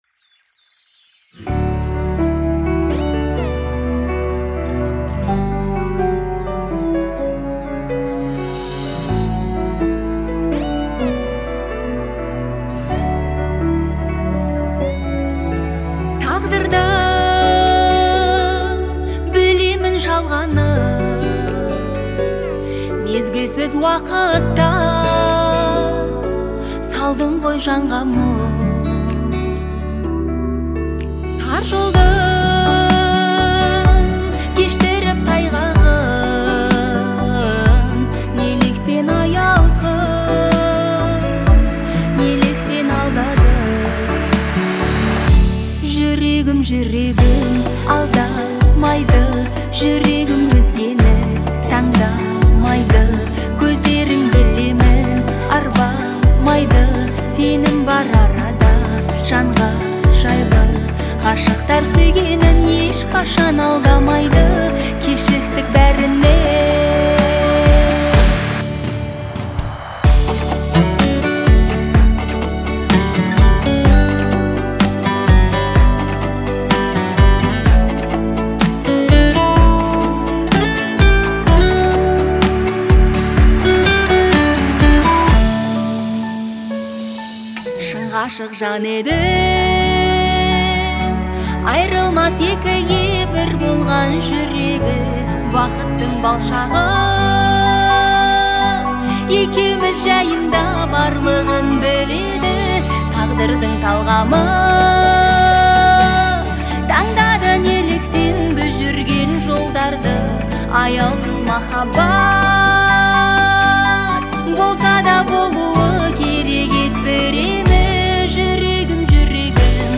эмоциональная и мелодичная песня казахстанской певицы